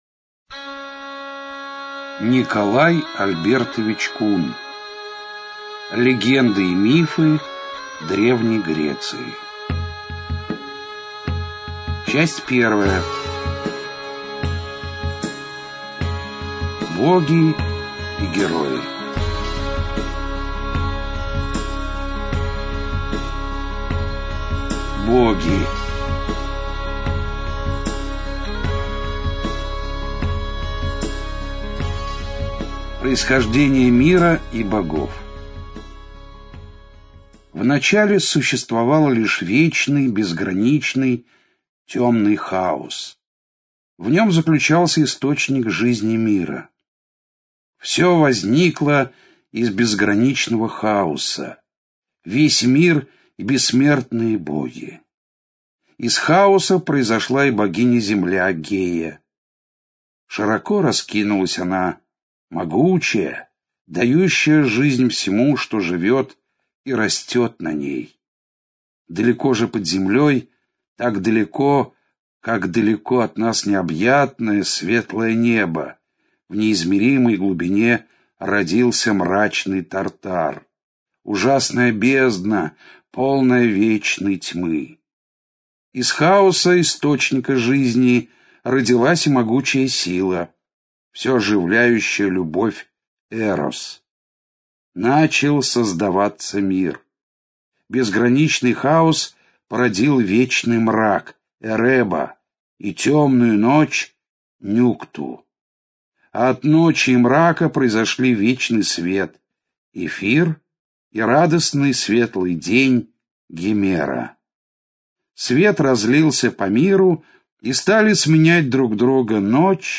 Аудиокнига Легенды и мифы Древней Греции - купить, скачать и слушать онлайн | КнигоПоиск